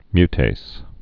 (mytās, -tāz)